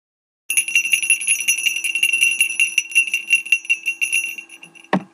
campanelli.wma